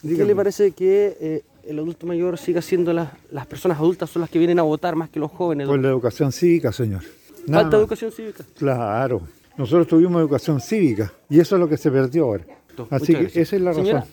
En conversación con Radio Sago, una de las personas manifestó que la ausencia de jóvenes en las urnas es por la falta de educación cívica.